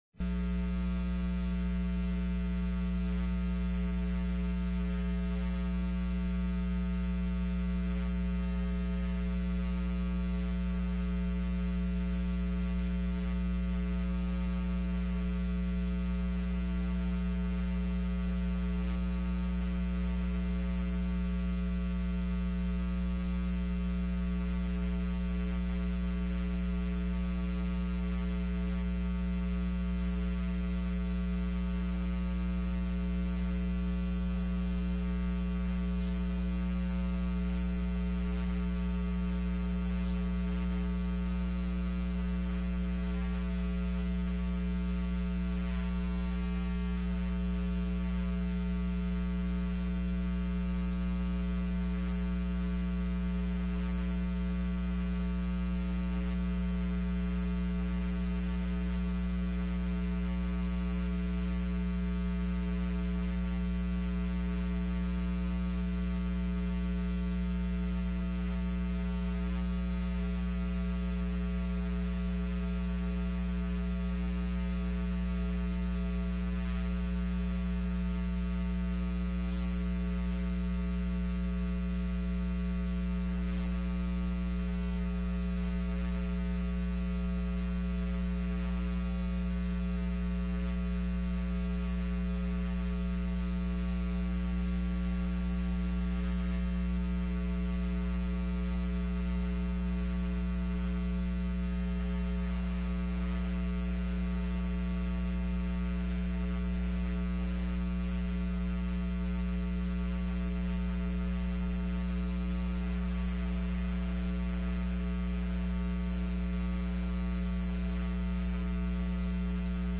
04/12/2013 01:30 PM House FINANCE